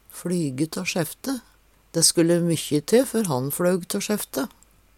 flyge tå sjefte - Numedalsmål (en-US)